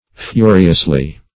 Fu"ri*ous*ly, adv.